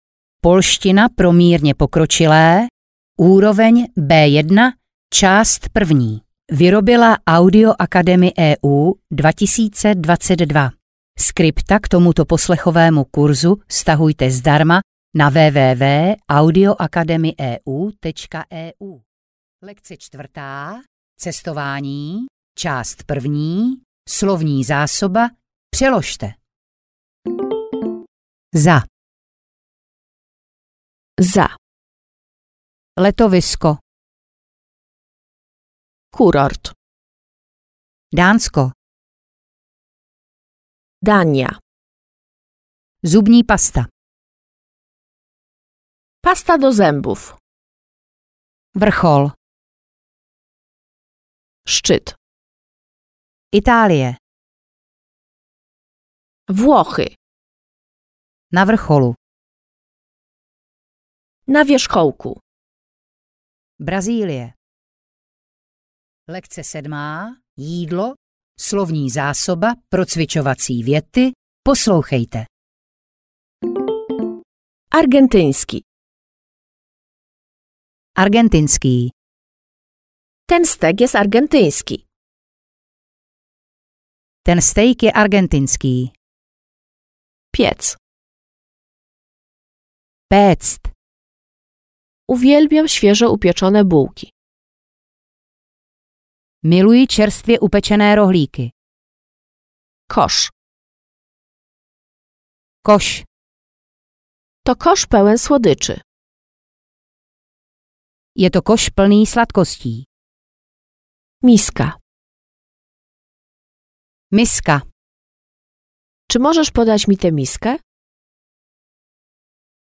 Audio knihaPolština pro mírně pokročilé B1, část 1
Ukázka z knihy